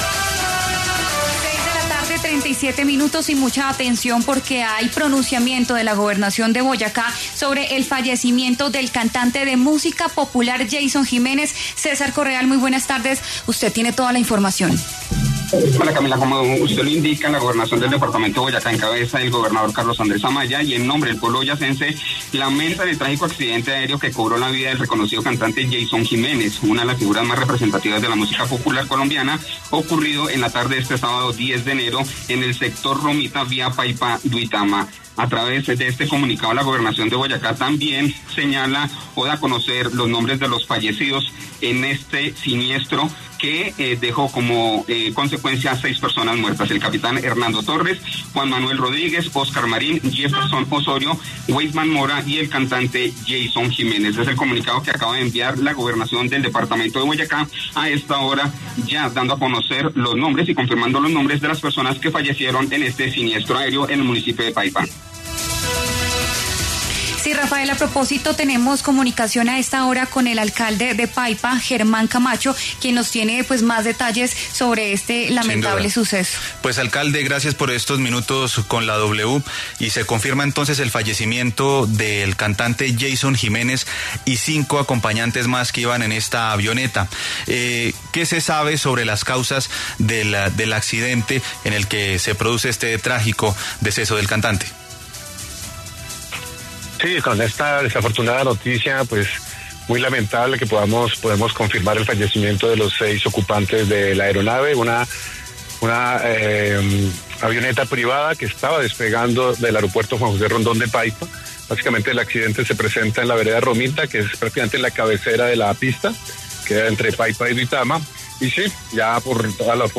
Germán Camacho, alcalde de Paipa, conversó con W Radio sobre el accidente aéreo en Boyacá en el que murió el cantante Yeison Jiménez.